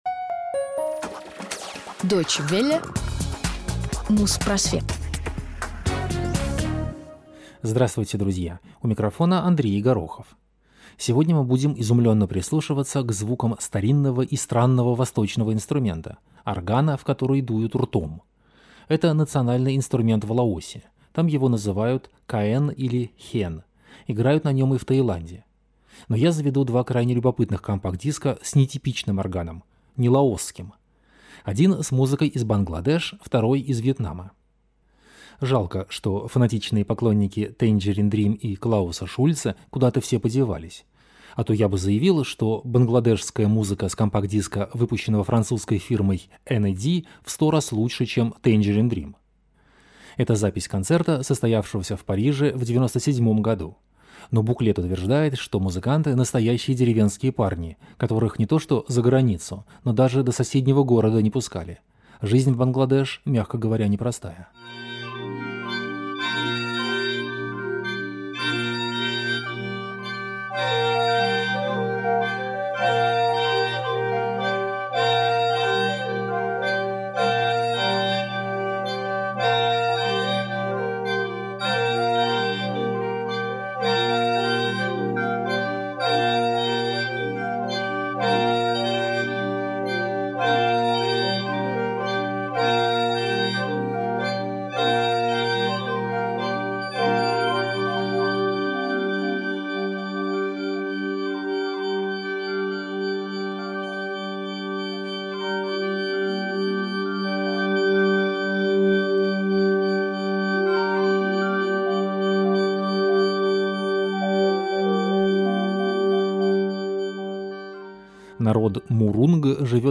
Музпросвет 306 от 21 июня 2008 года - Органная музыка Бангладеш и Вьетнама | Радиоархив